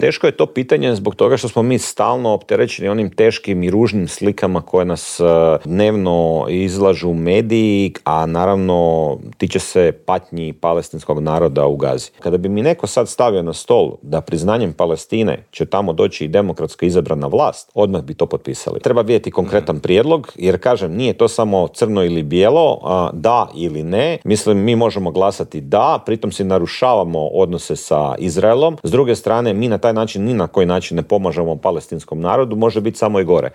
ZAGREB - "Bilo je i vrijeme da se gospodarske mjere relaksiraju, građani sada moraju preuzeti dio tereta na sebe. To neće ubrzati rast inflacije, a važno je da pomognemo onima koji su najugroženiji", u Intervjuu tjedna Media servisa poručio je saborski zastupnik iz redova HSLS-a Dario Hrebak.